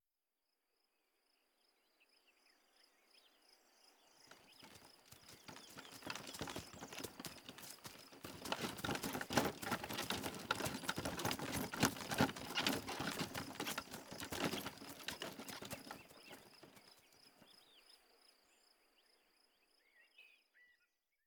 Pasada de un carro de caballos
calesa
carruaje
Sonidos: Rural